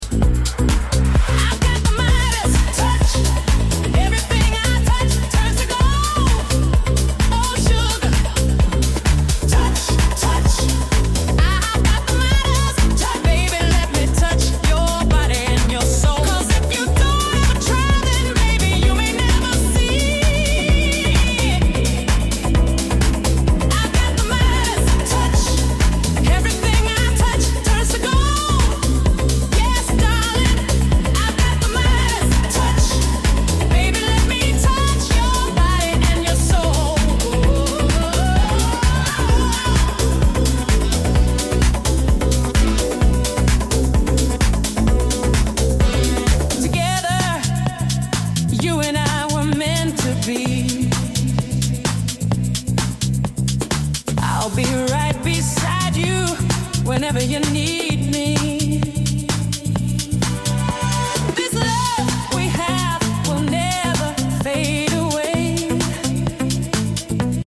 MinimalHouse